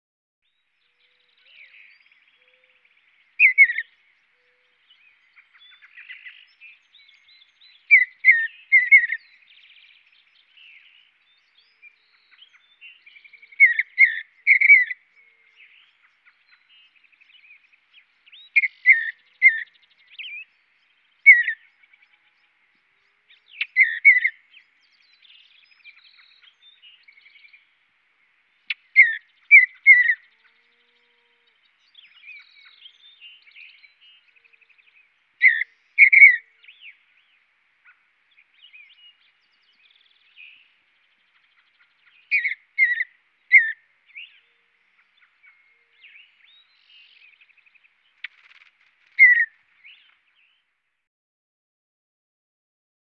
Bluebird, Mountain Chirps. A Bluebird Chirps And Flutters In The Foreground While Other Birds Chirp In The Background.